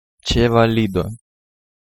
Ääntäminen
Synonyymit yearling Ääntäminen France: IPA: [ɛ̃ pu.lɛ̃] Tuntematon aksentti: IPA: /pu.lɛ̃/ Haettu sana löytyi näillä lähdekielillä: ranska Käännös Ääninäyte Substantiivit 1. ĉevalido 2. novulo Suku: m .